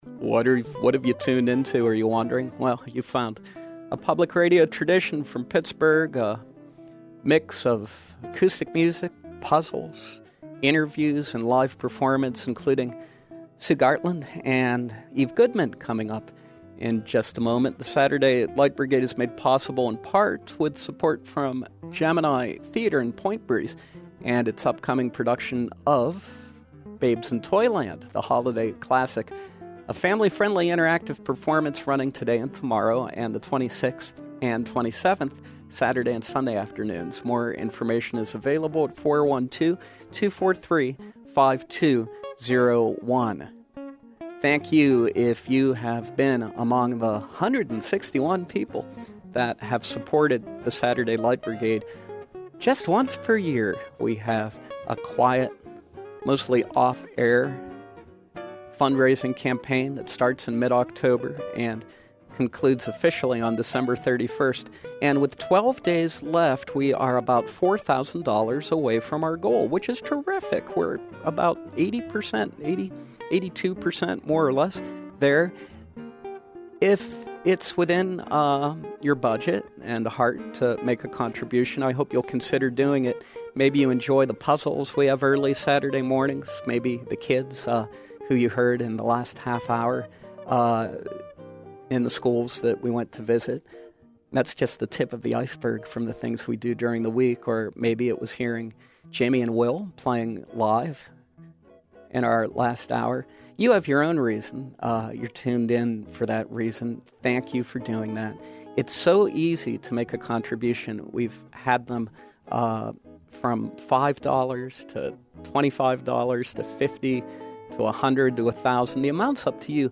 talented singer-songwriter
second guitar and harmony
lovely folk songs live in our studios